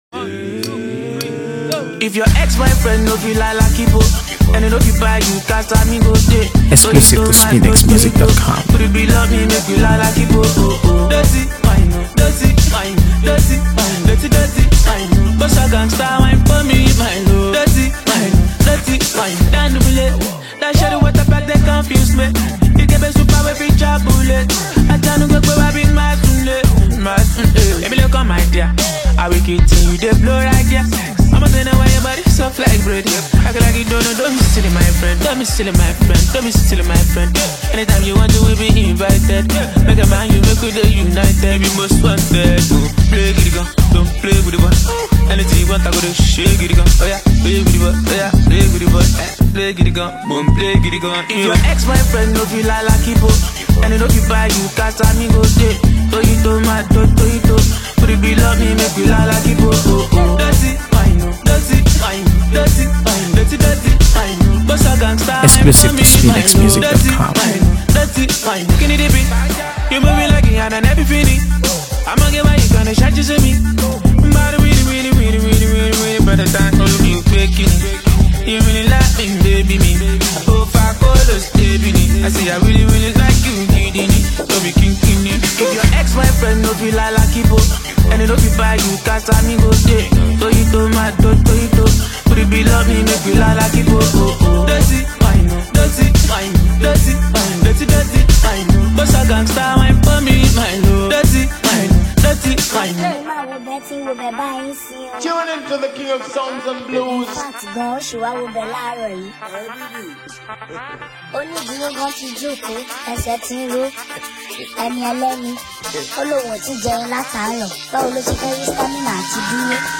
AfroBeats | AfroBeats songs
blends rhythm, energy, and unmistakable swagger
With its vibrant production and catchy flow